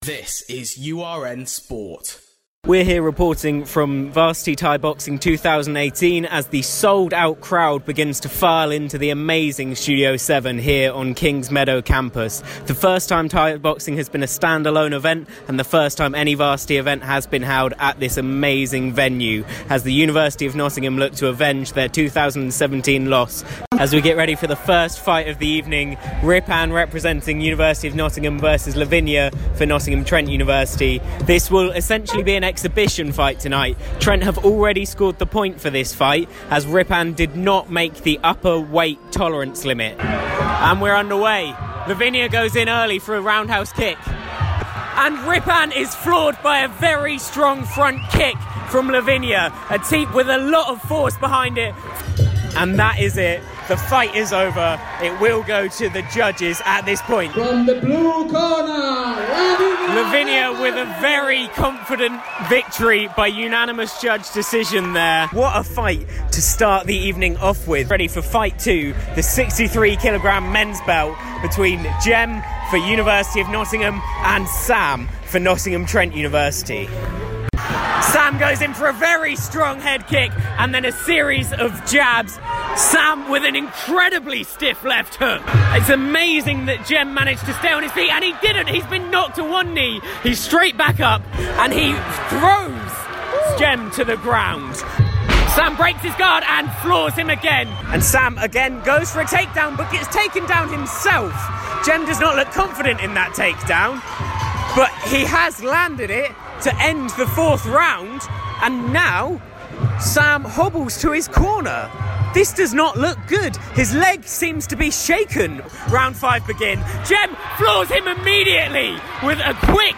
Withe the series at 2-1 to the University of Nottingham, Monday night saw the debut of Thai Boxing held on King's Meadow Campus at Studio 7.